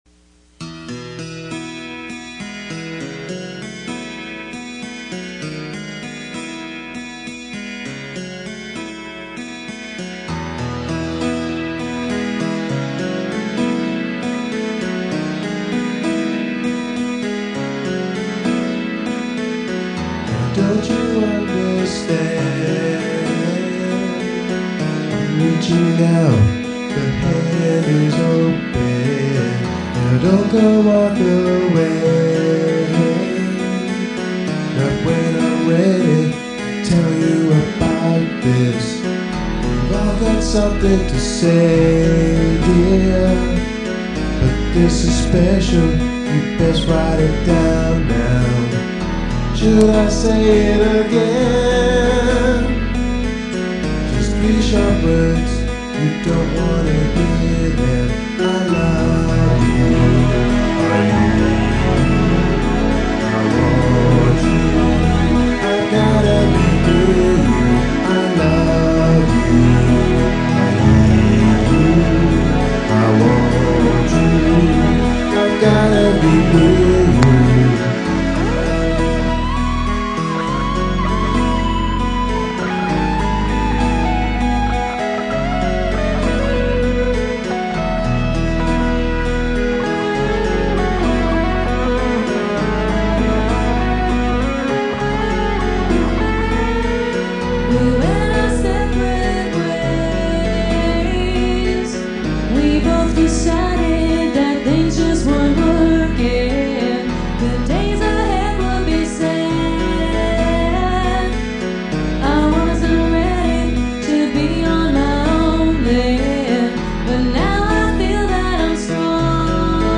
Songs with vocals
A duet for he and she. Lyrics Pop Ballad 1,504 KB 3:39